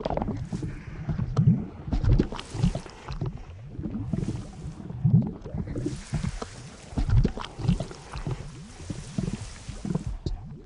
buzz_idle.ogg